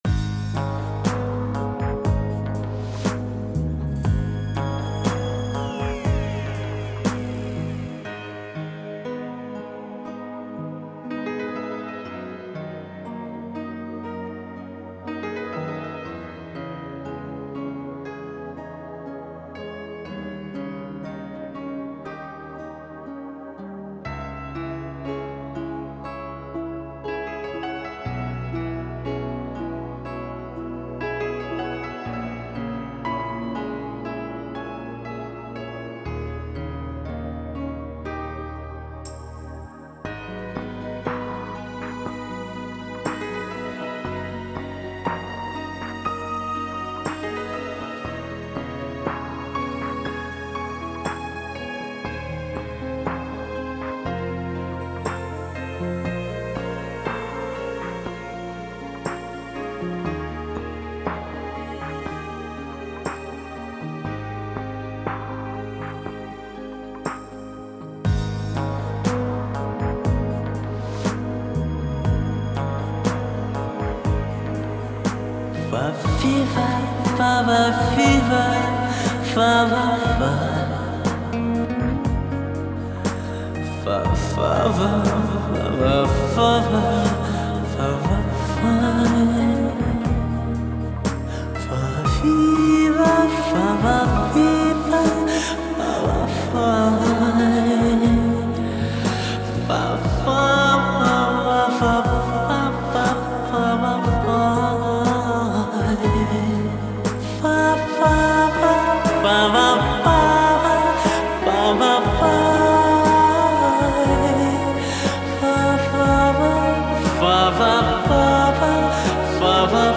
匪夷所思的高音，更具有不可思议的海豚音。
高音区更加透彻明亮，中低音区也日趋饱满圆润